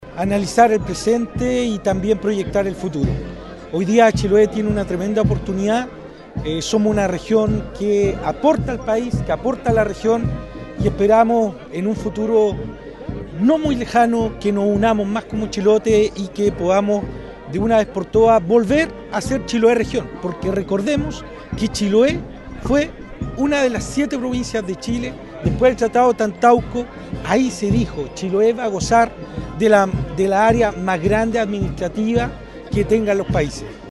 El Bicentenario de Chiloé se conmemoró en el Fuerte San Antonio de Ancud, región de Los Lagos, donde autoridades civiles y militares, representantes huilliches y distintas organizaciones se reunieron para recordar la historia del archipiélago y analizar sus desafíos actuales.
Desde el ámbito local, el alcalde de Ancud, Andrés Ojeda, planteó que el momento es oportuno para reflexionar y proyectar el futuro.